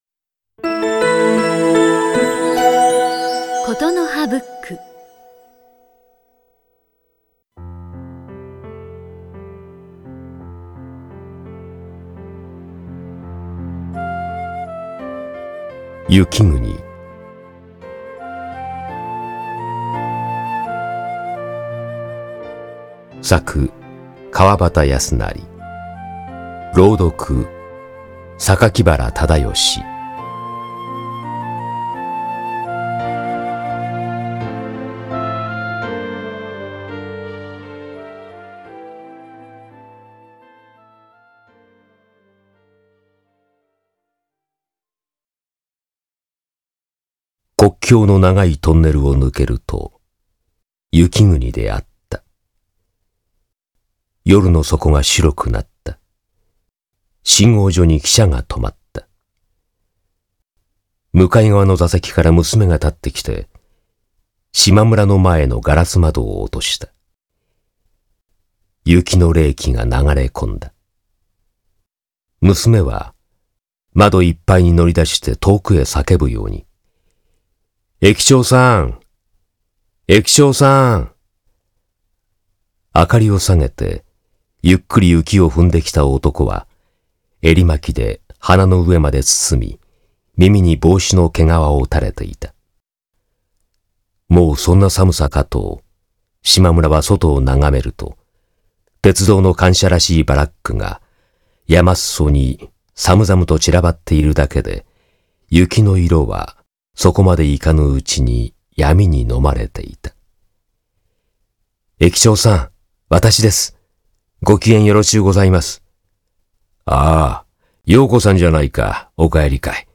[オーディオブック] 雪国